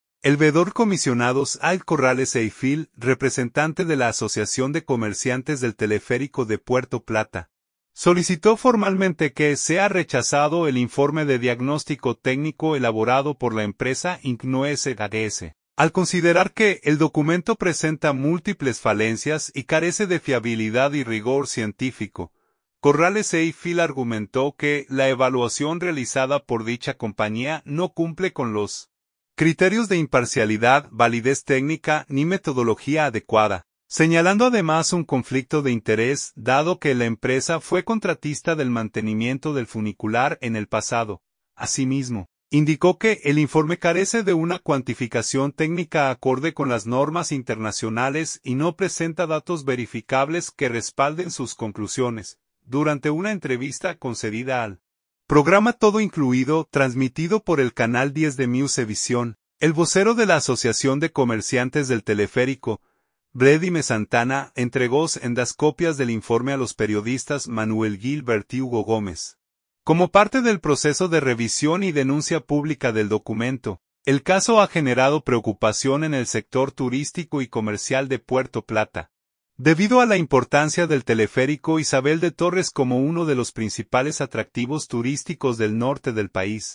Durante una entrevista concedida al programa “Todo Incluido”